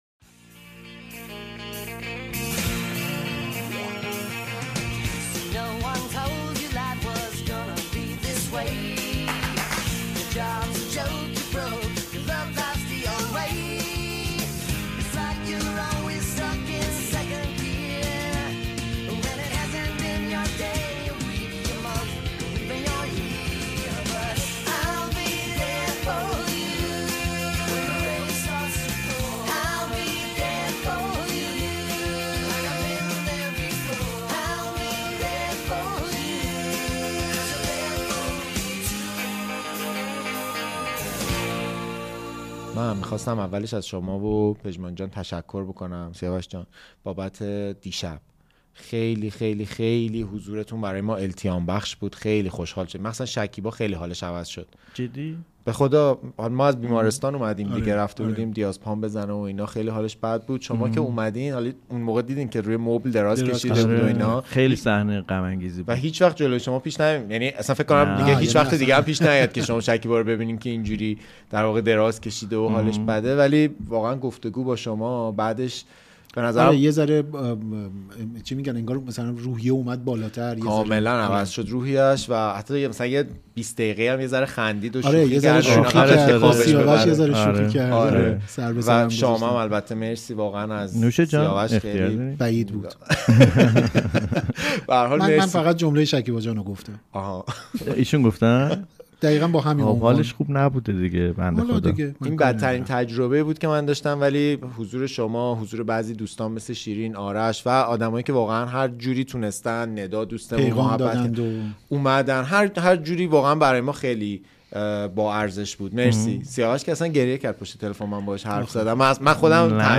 این شما و این هم اپیزود چهاردهم از فصل چهارم پادکست هاگیرواگیر که چند روز پس از به قتل رسیدن کارگردان بزرگمون جناب داریوش مهرجویی و همسر گرامی‌شون وحیده محمدی‌فر و در میانه‌ی جنگ غزه و پیش از خبر از دست رفتن دختر عزیزمون آرمیتا گراوند٬ ضبط و منتشر شد. این صدا و این گفتگوها بماند به یادگار از ما سه نفر در زمانه‌ی لبریز از هاگیرواگیر.